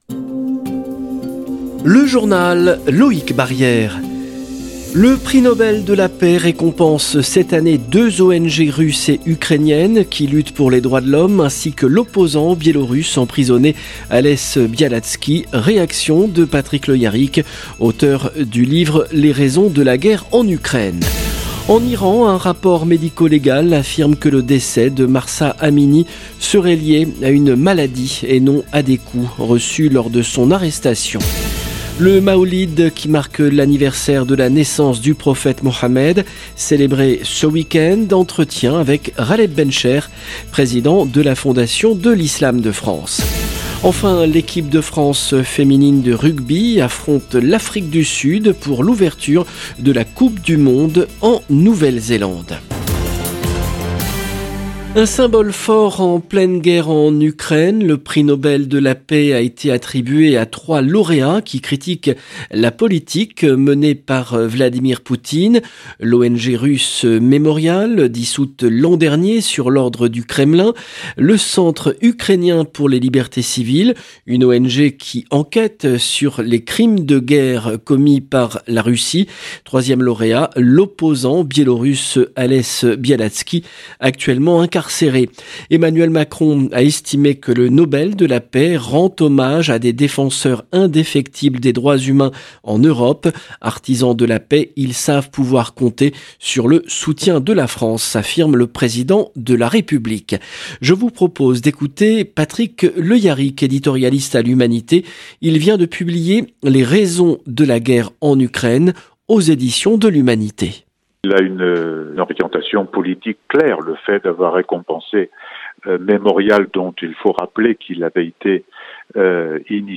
LE JOURNAL EN LANGUE FRANCAISE DU SOIR DU 7/10/22